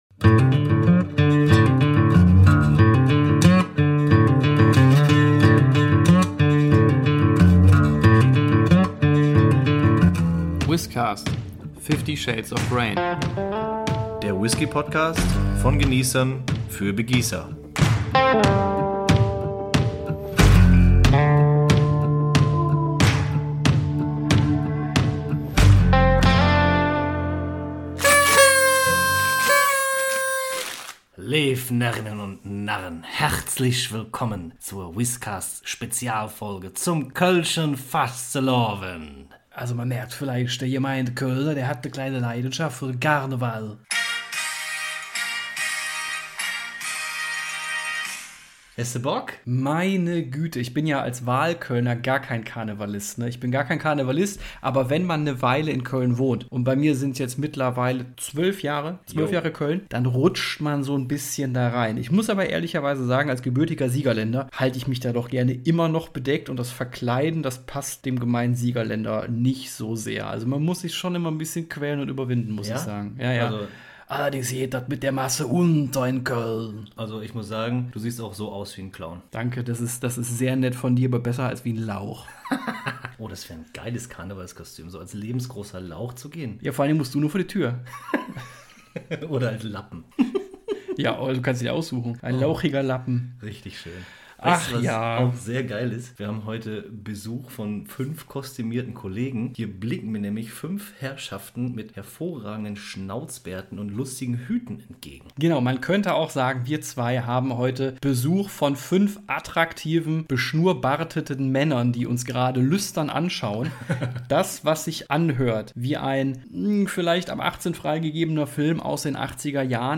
Bitte erschreckt euch nicht beim jecken jetrööte unserer tollen Karnevals-Episode!